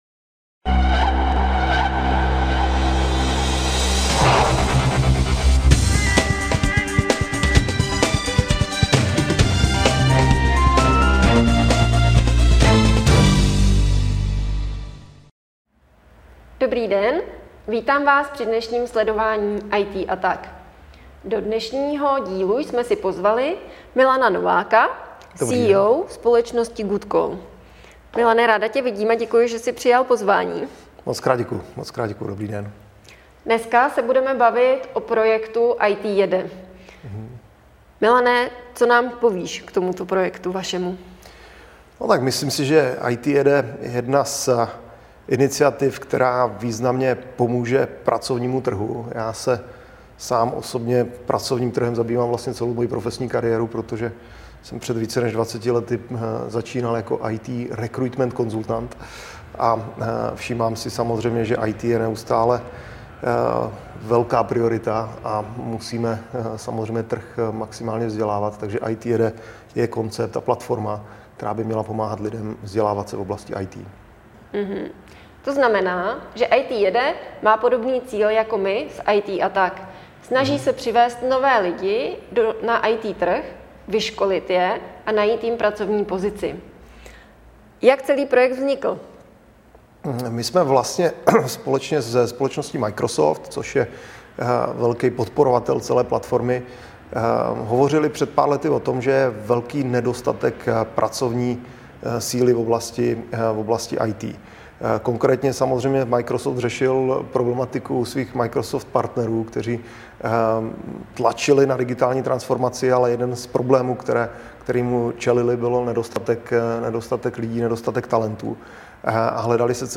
V našem rozhovoru